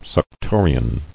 [suc·to·ri·an]